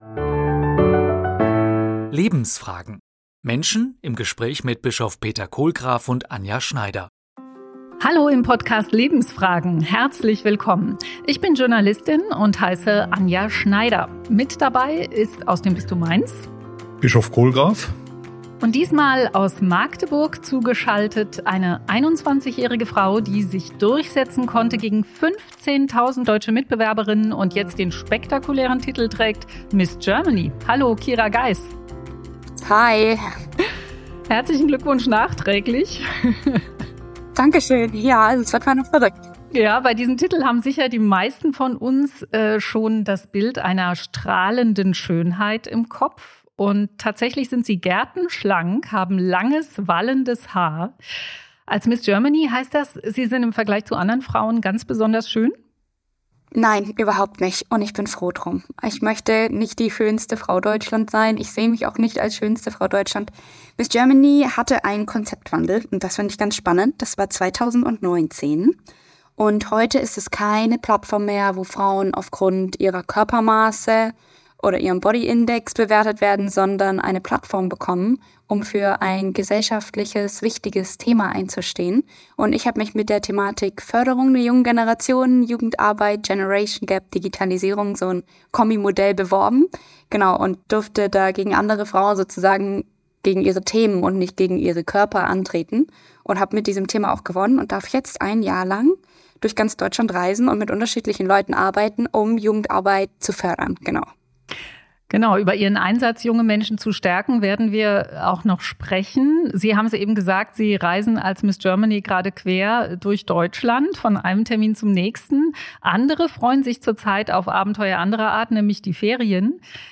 Lebensfragen - Menschen im Gespräch
zu Gast: Kira Geiss Thema: Wendepunkte – die Kurve kriegen Sie ist die amtierende Miss Germany.